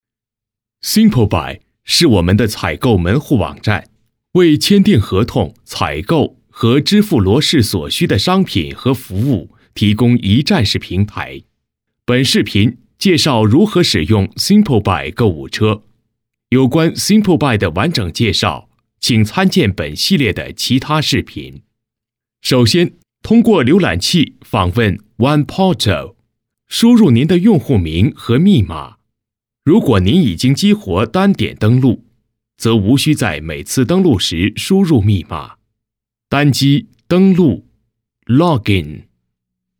男英10_外语_国内英语_中英文双语最新效果特好.mp3